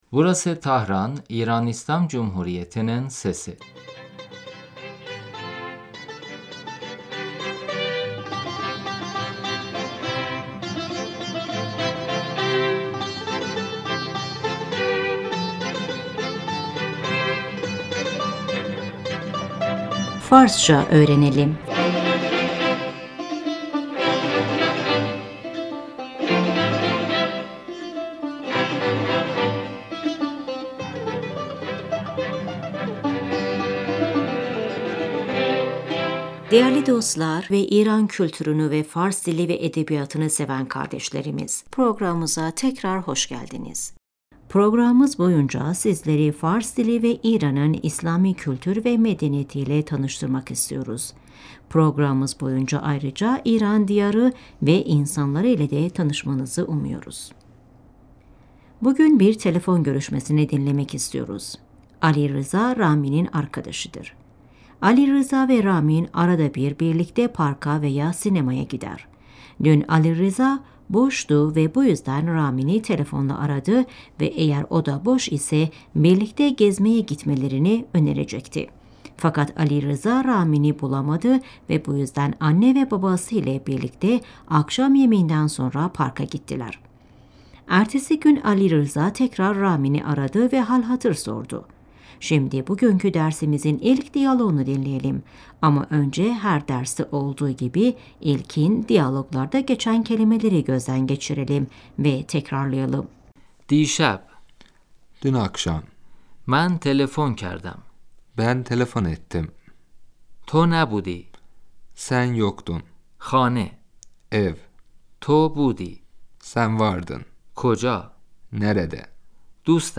Bugün bir telefon görüşmesini dinlemek istiyoruz.
صدا ازطریق تلفن شنیده می شود Telefondan duyulan ses علیرضا – سلام رامین، دیشب تلفن کردم .